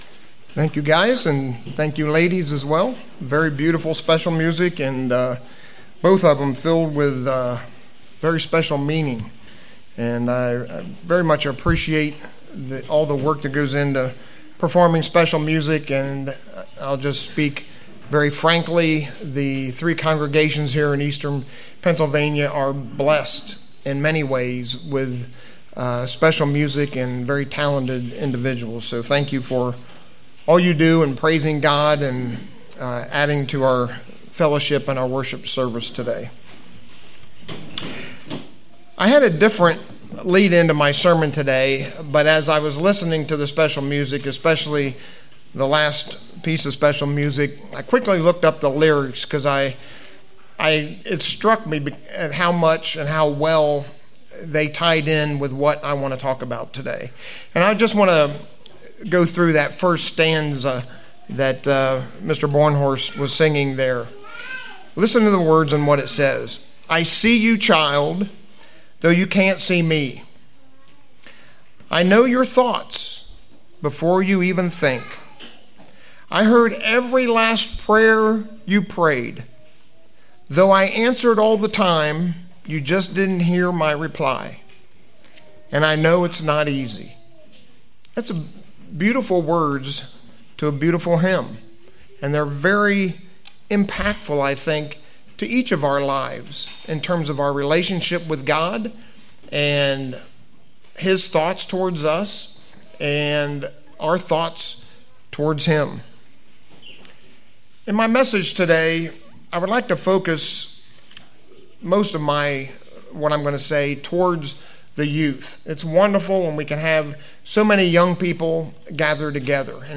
Sermons
Given in Lewistown, PA